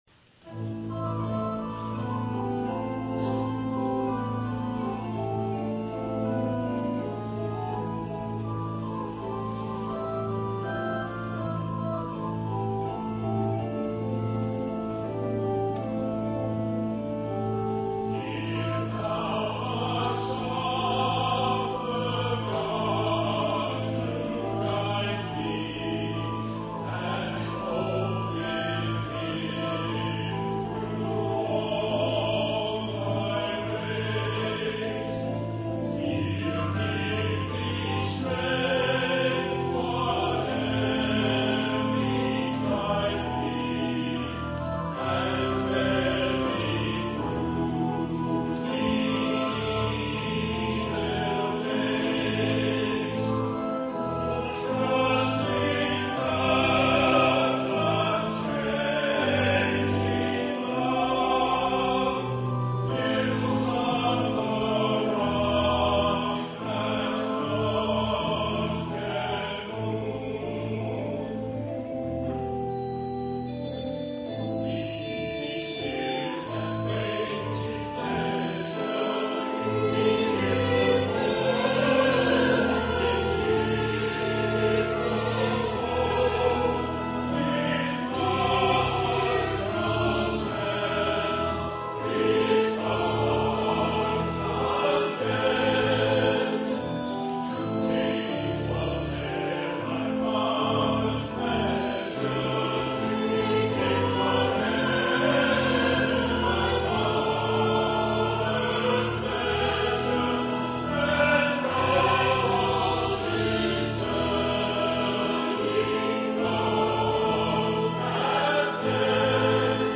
Anthems